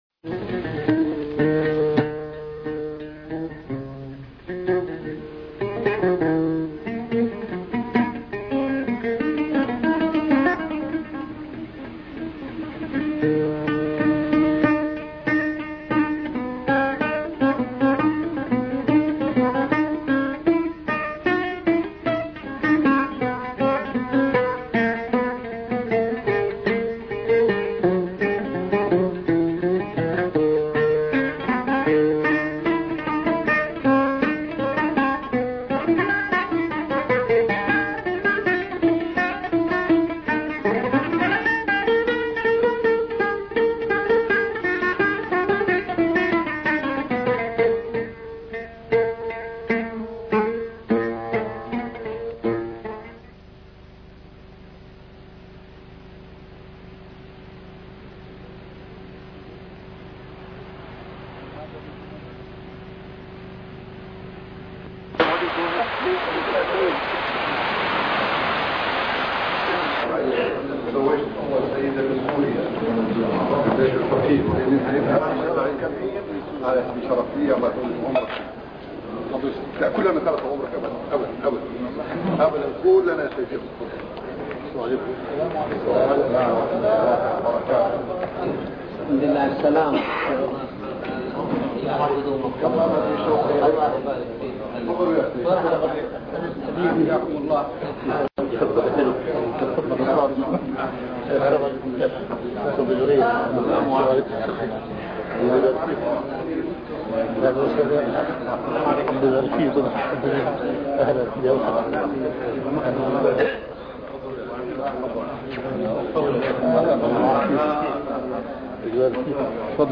A MARTYR SCHOLAR: IMAM MUHAMMAD SAEED RAMADAN AL-BOUTI - الدروس العلمية - محاضرات متفرقة في مناسبات مختلفة - كلمة الإمام الشهيد البوطي في الاثنينيه
محاضرات متفرقة في مناسبات مختلفة - A MARTYR SCHOLAR: IMAM MUHAMMAD SAEED RAMADAN AL-BOUTI - الدروس العلمية - كلمة الإمام الشهيد البوطي في الاثنينيه